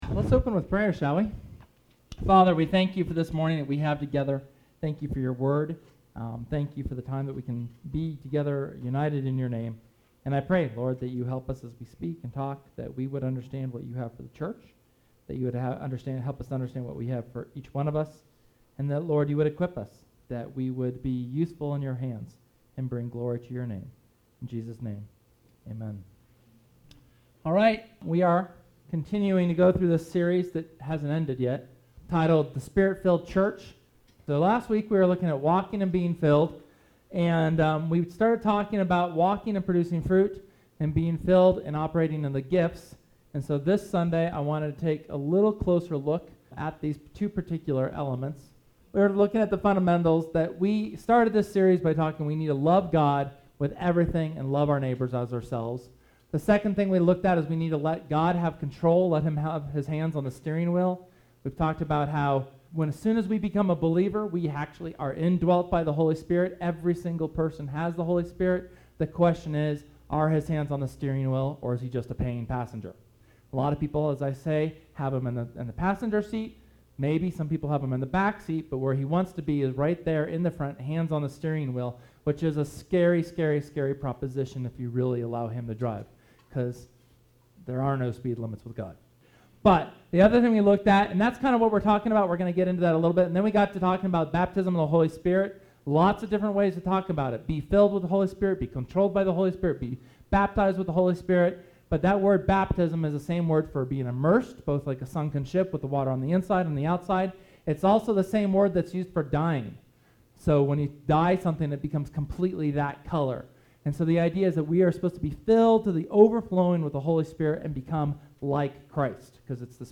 SERMON: The Fruit & Gifts of the Spirit (HS #8)
Sermon from August 31st discussing the fruit and the gifts of the Holy Spirit.